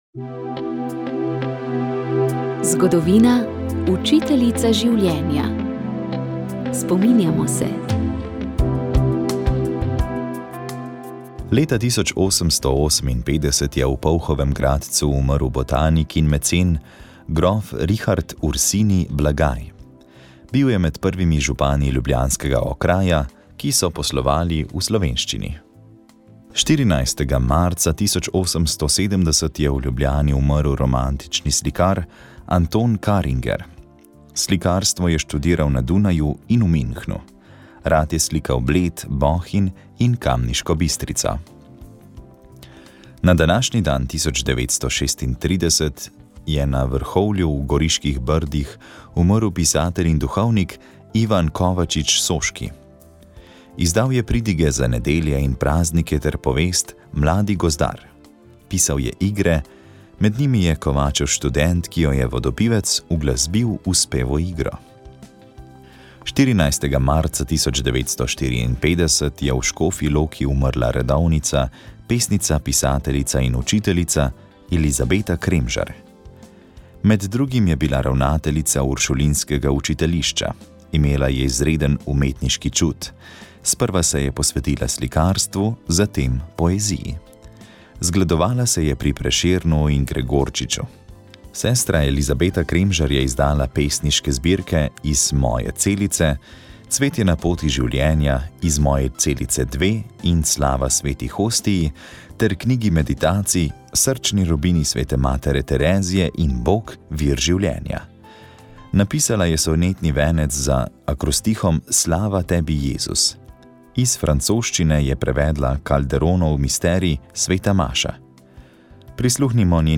Vremenska napoved 14. februar 2025